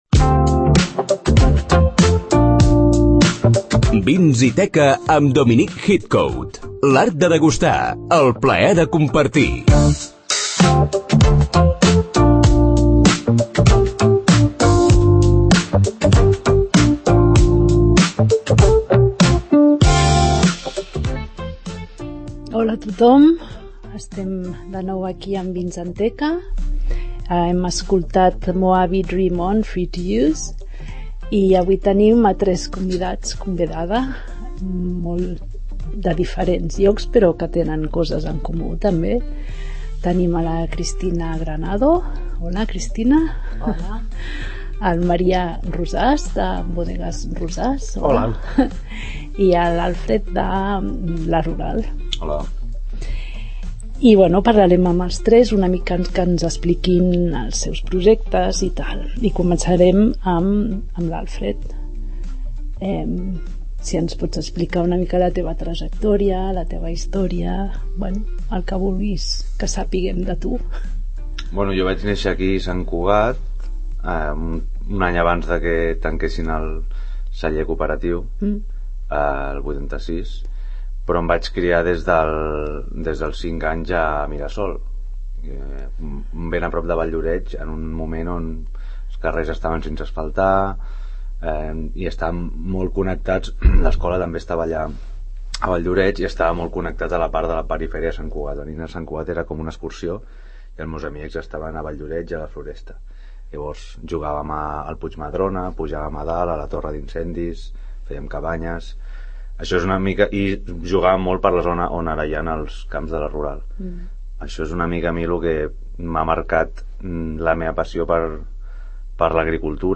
El p�dcast especialitzat en vins i gastronomia 'Vins & teca' estrena un nou cap�tol, amb convidats del sector vitivin�cola i de la restauraci�.